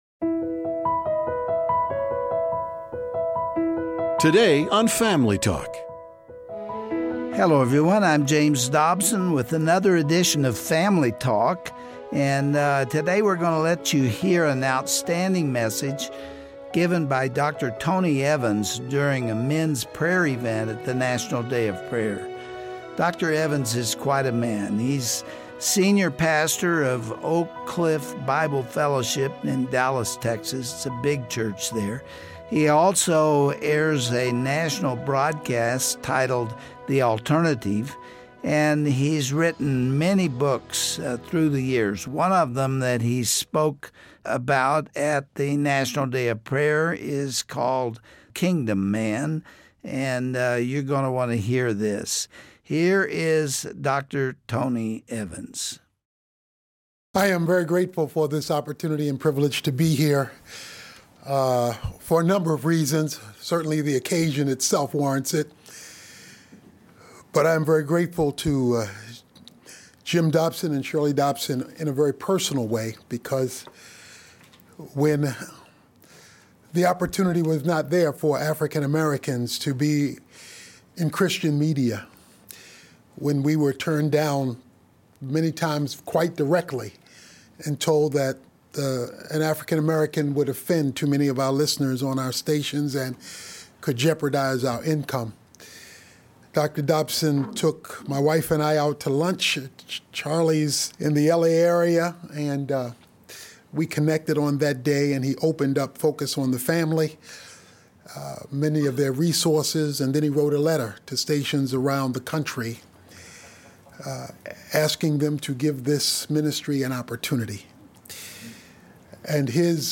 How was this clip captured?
Any man can be a world-changer if he fully understands the role God has given him in his family, at work and in the community. Listen to this powerful speech from the National Day of Prayer to discover how to pursue biblical manhood and become a Kingdom Man.